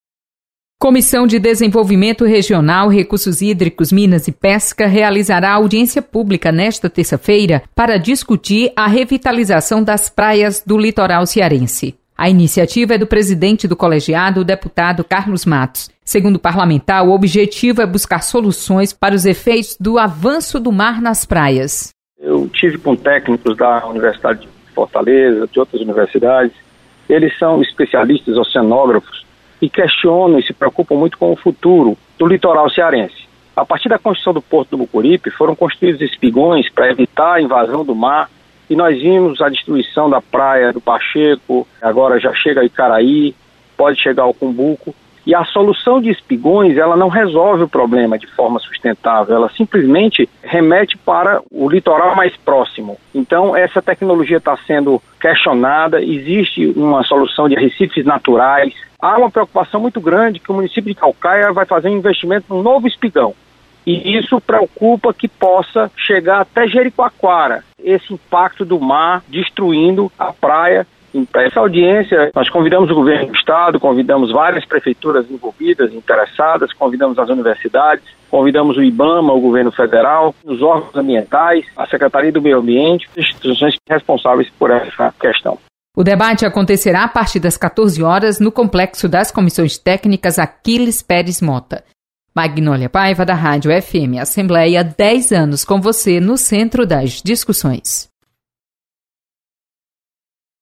Assembleia vai debater revitalização das praias cearenses. Repórter